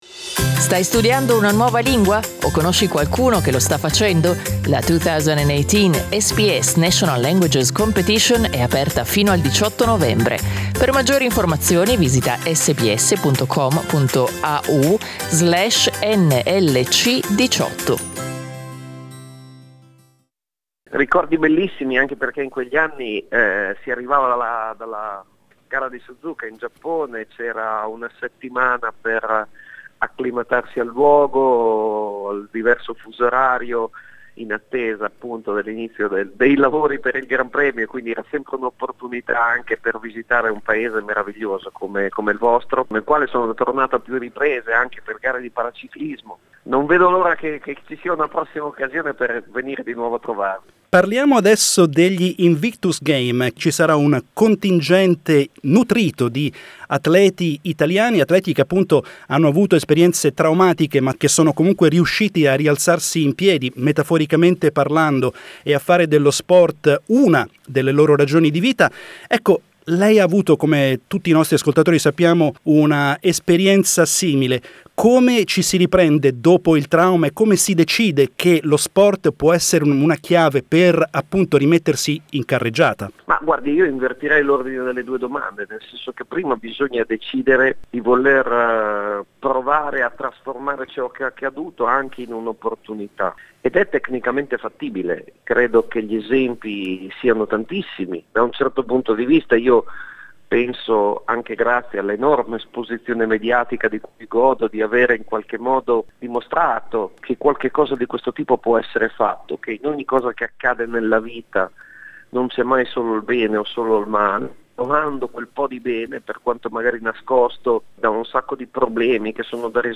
We interviewed Alex Zanardi on the occasion of the Invictus Games, an annual sporting event that includes competitions in different sports disciplines between war veterans who have permanent disabilities.